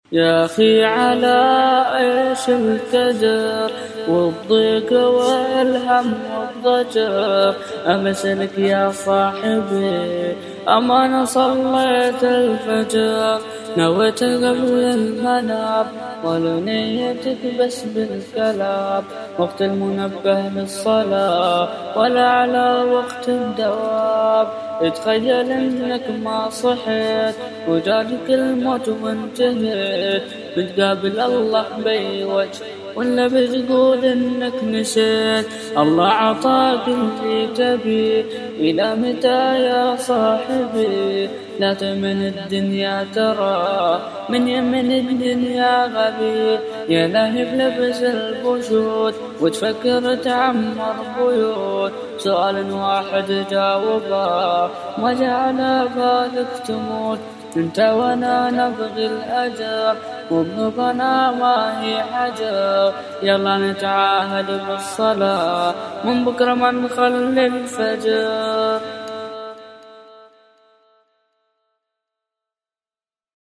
صلاة الفجر - إنشاد